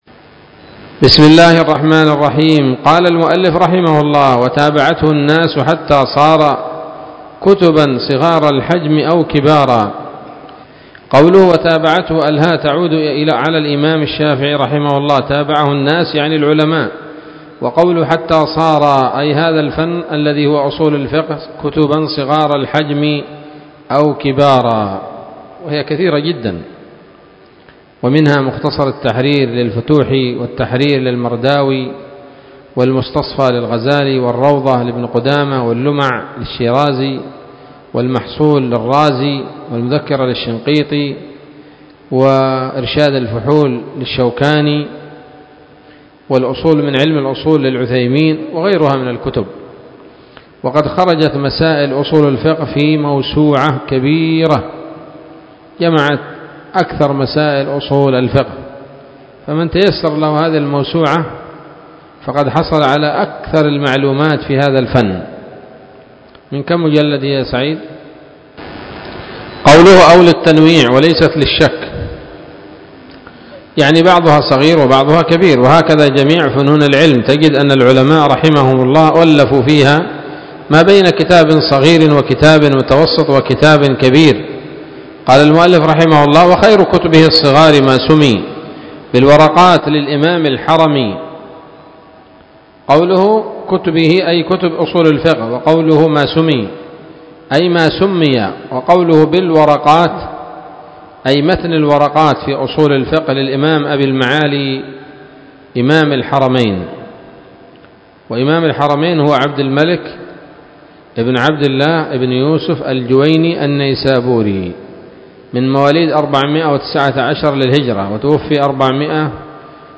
الدرس الثالث من شرح نظم الورقات للعلامة العثيمين رحمه الله تعالى